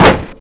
Index of /server/sound/vcmod/collision/light